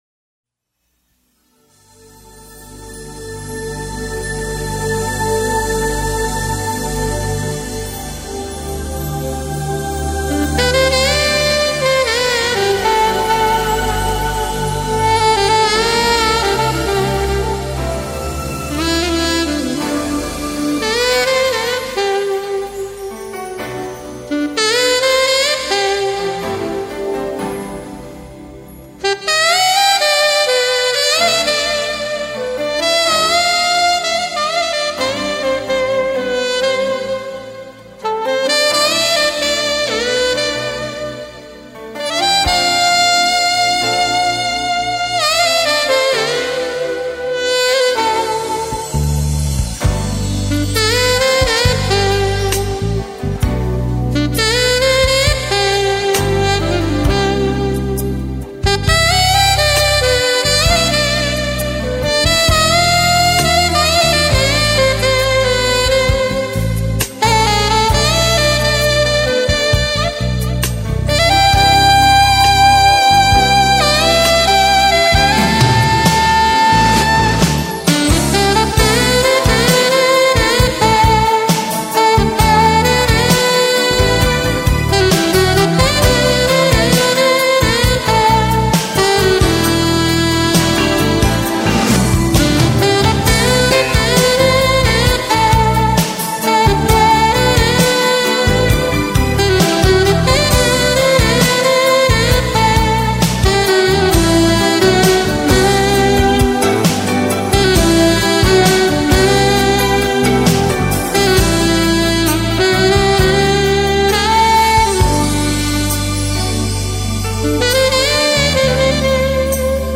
2006-04-29 오후 6:48:00 "약속" 어떤 약속이길래 이렇게 애절하게 흘러가는걸까요?